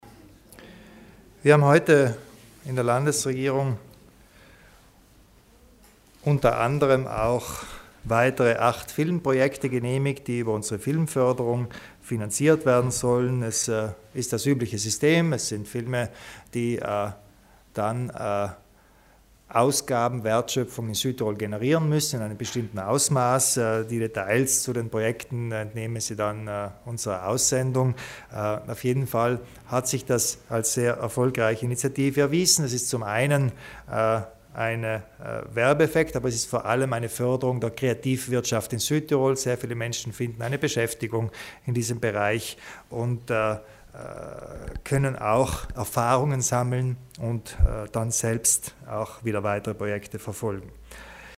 Landeshauptmann Kompatscher zu den neuen Projekten der Filmförderung